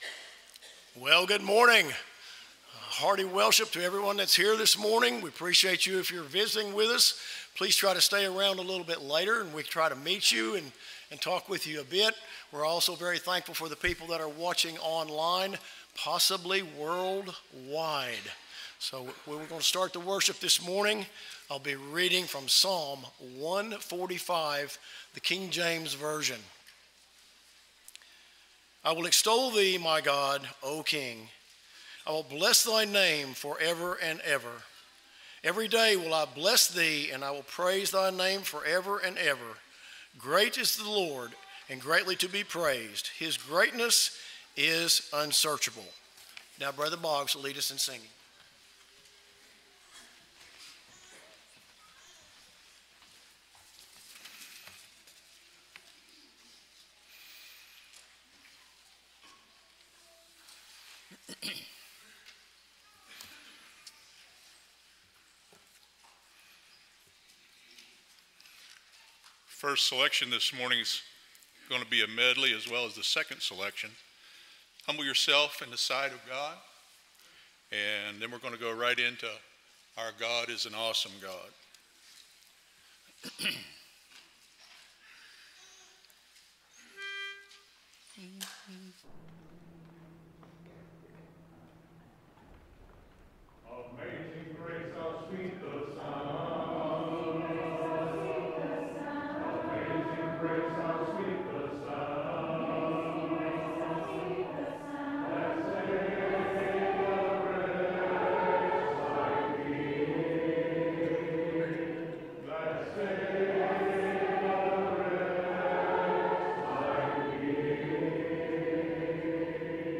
John 15:13, English Standard Version Series: Sunday AM Service